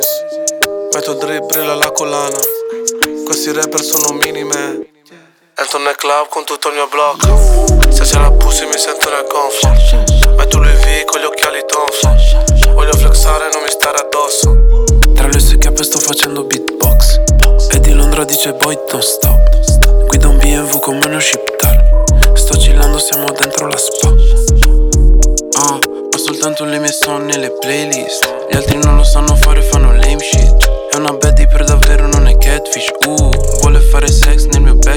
Rap Hip-Hop Rap
Жанр: Хип-Хоп / Рэп